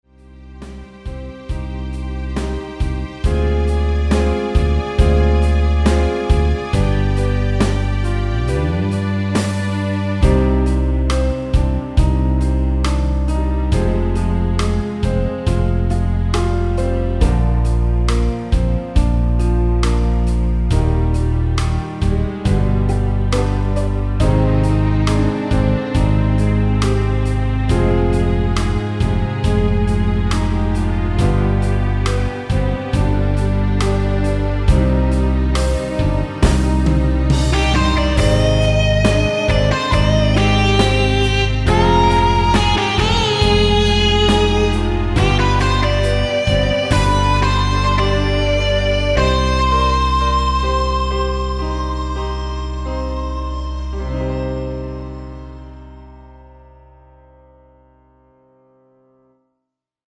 원키에서(-2)내린 MR입니다.
Cm
앞부분30초, 뒷부분30초씩 편집해서 올려 드리고 있습니다.